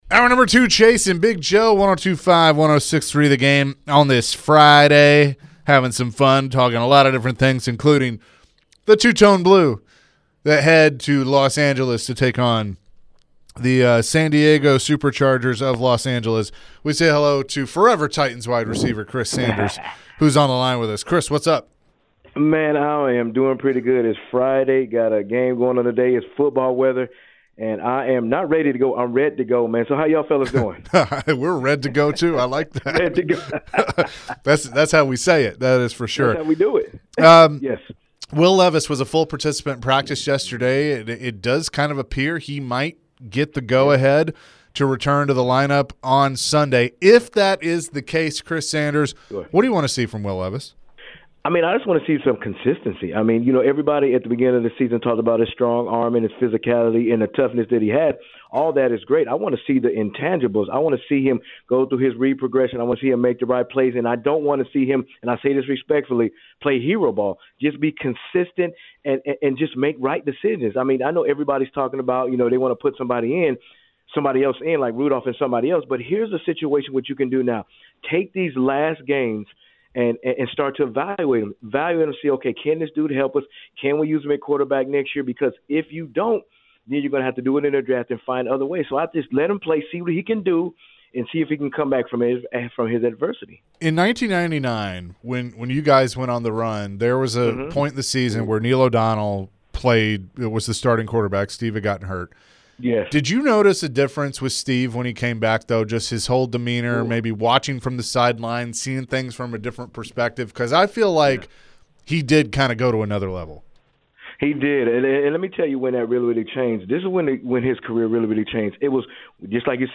Forever Titans wide receiver Chris Sanders joined the show and shared his thoughts about the upcoming matchup at the Chargers. Chris shared his thoughts on Calvin Ridley and the possibility of Will Levis playing Sunday.